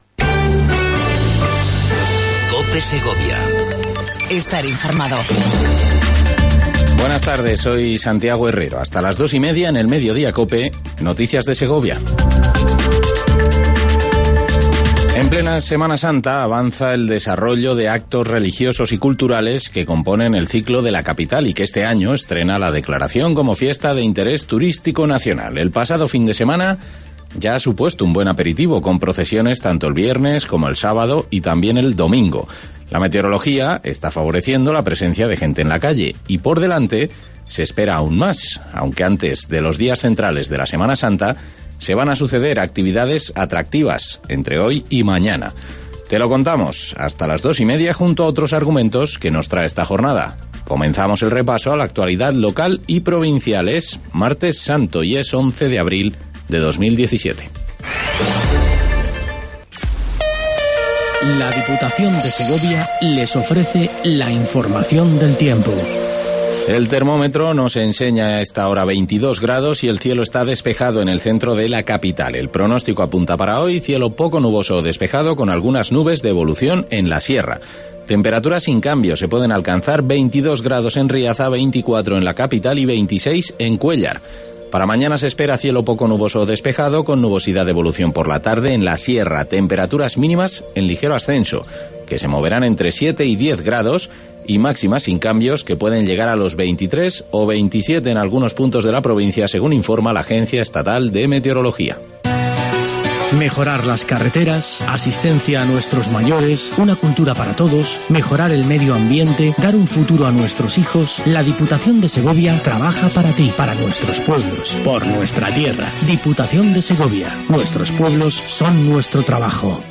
INFORMATIVO MEDIODIA COPE EN SEGOVIA 11 04 17